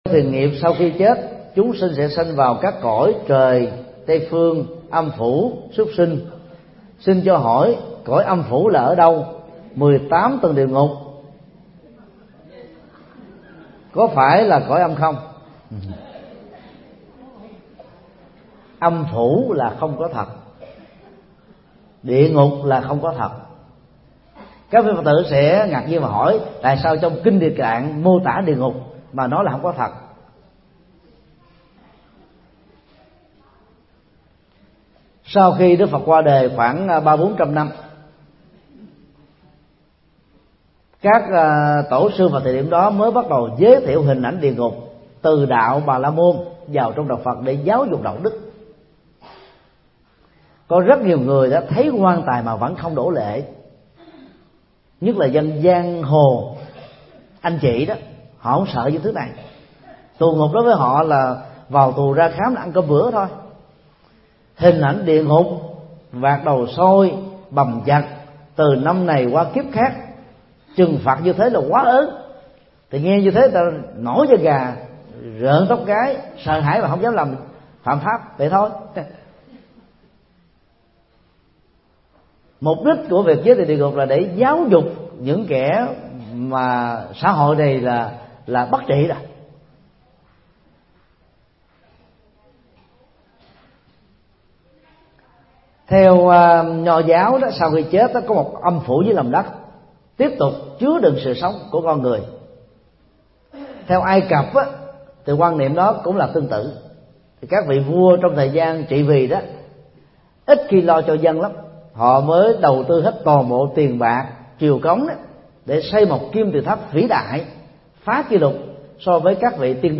Vấn đáp: Khái niệm về âm phủ, 18 tầng địa ngục – Thầy Thích Nhật Từ